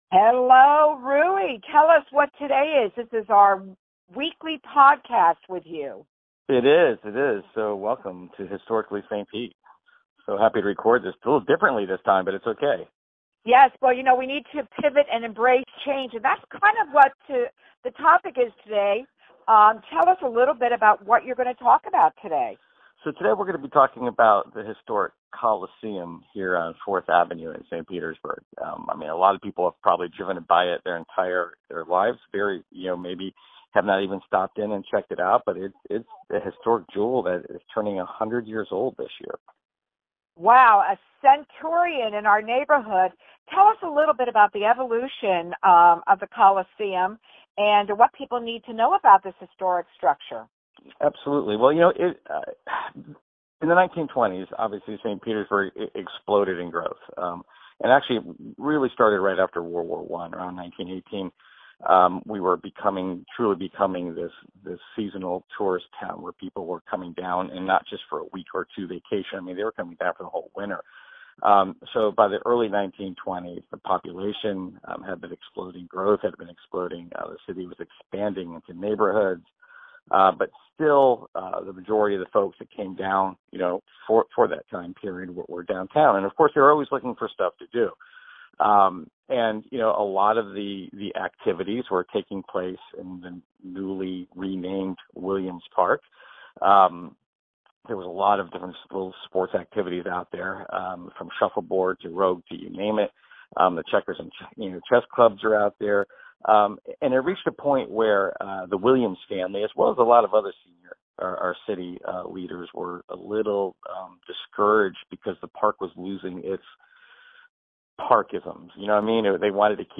weekly conversation